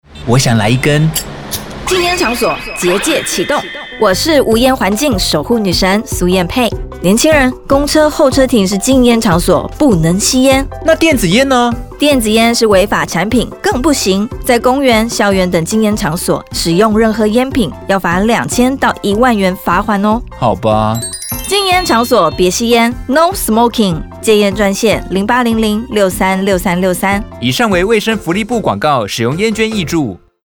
衛生福利部_無菸結界篇廣播檔30秒.mp3